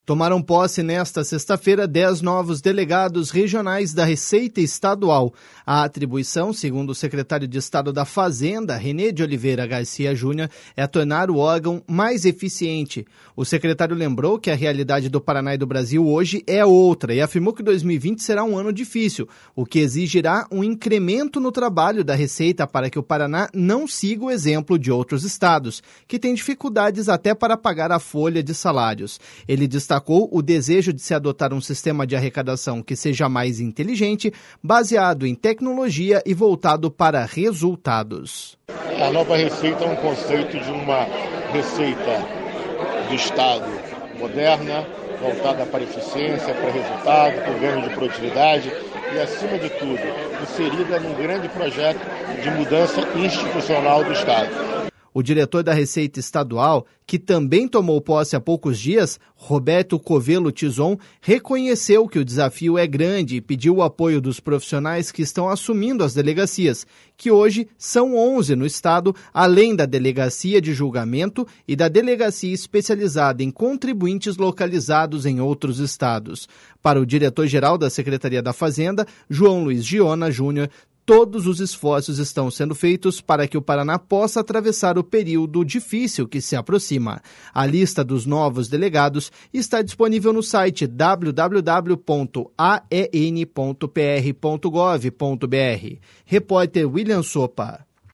Ele destacou o desejo de se adotar um sistema de arrecadação que seja mais inteligente, baseado em tecnologia e voltado para resultados.// SONORA RENÊ GARCIA.//